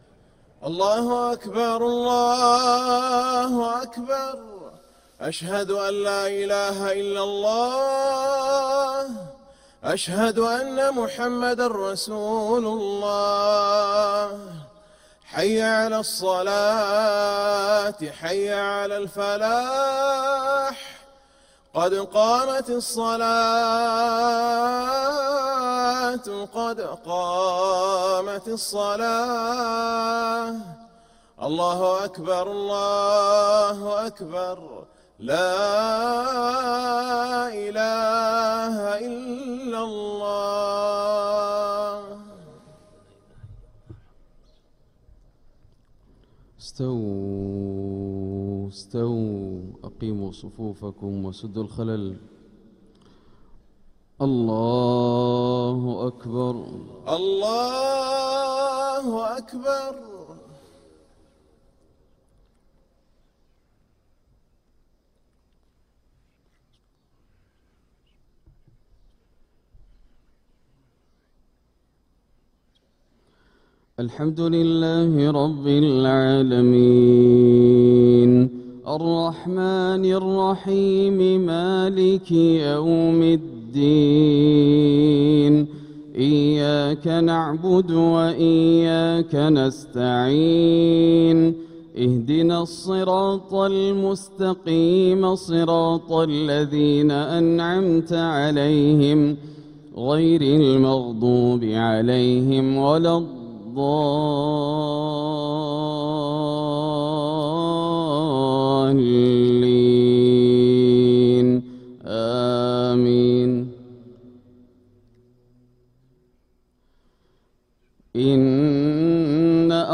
Makkah Fajr - 07th February 2026
Makkah Fajr (Surah Yasin 55-83) Sheikh Dosary Download 128kbps Audio